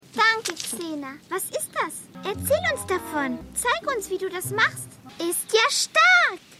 Xena_1x15_Maedchen.mp3